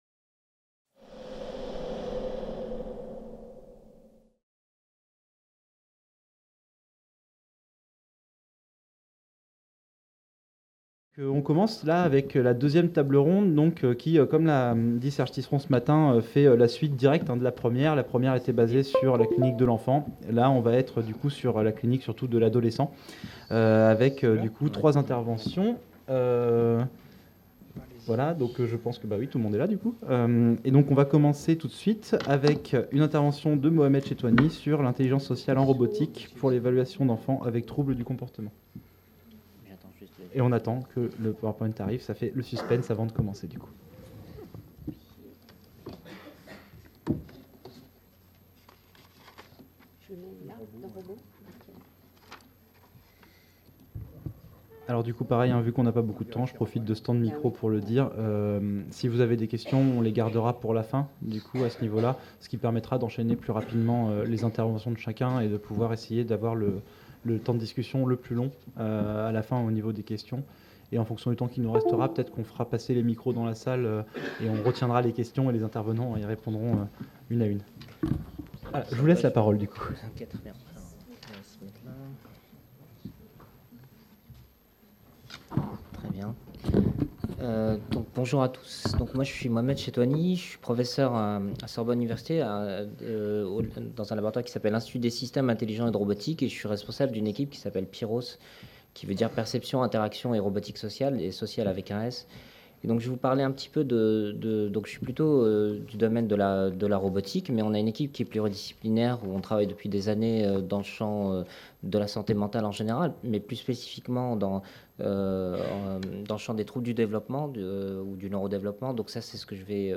2e table ronde : robots et RV dans la clinique de l’adolescent